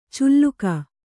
♪ culluka